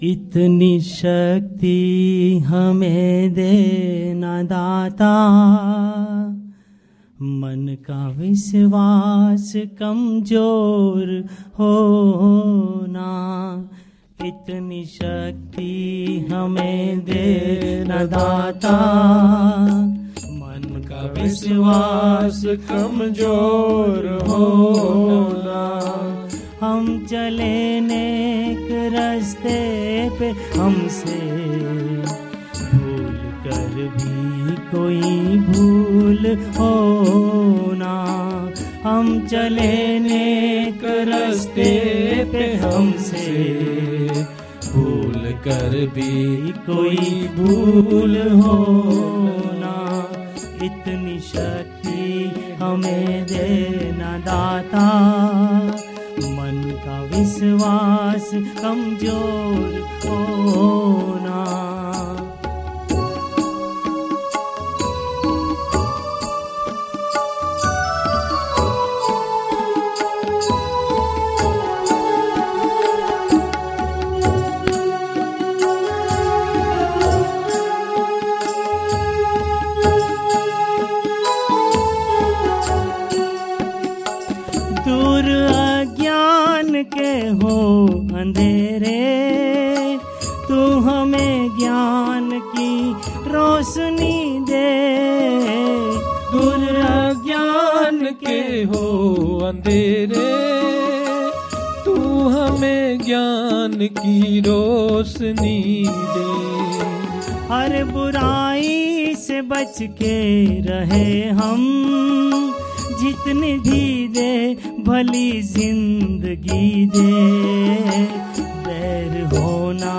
OUR Samaj held Shivratri 2006 celebrations with Jai bardai Melodies (the Samaj’s Music Group) and members from OUR Samaj performing bhajans.